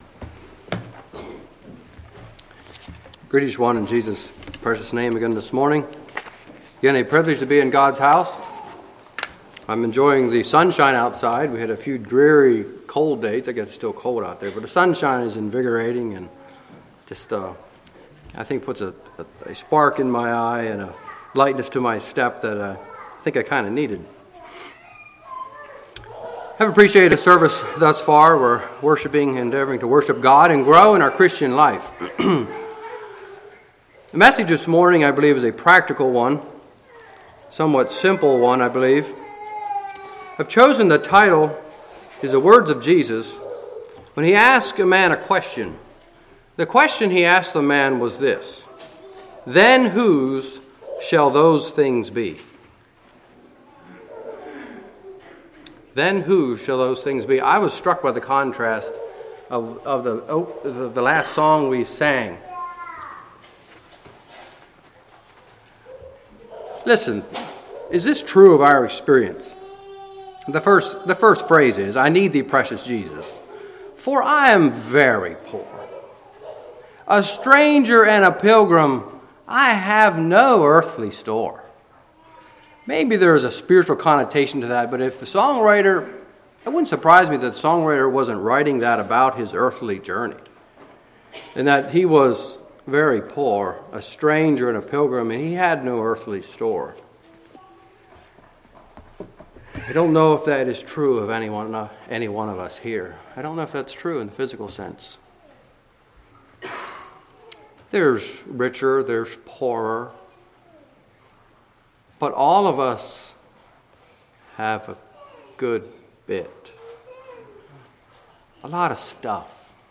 2014 Sermon ID